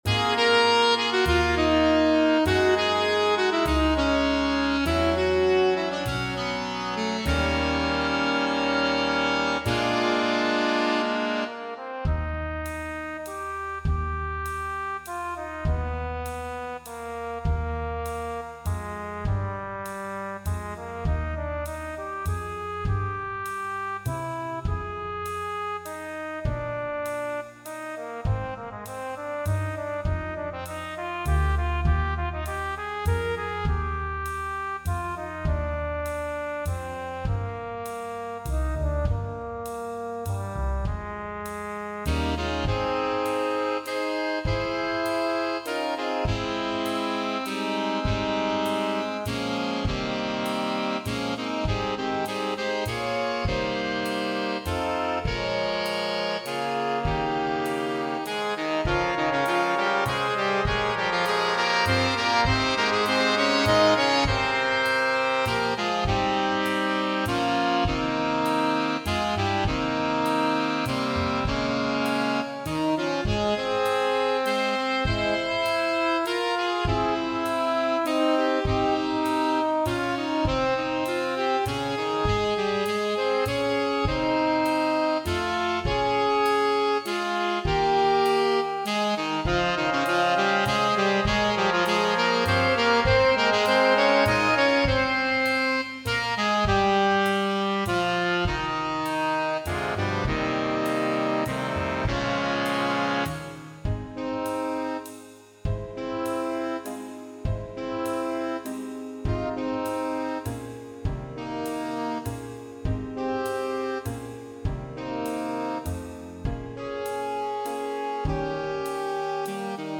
Big Band
All audio files are computer-generated.
A ballad (tempo = 100) flugelhorn feature and improvised solo. Piano part requires comping.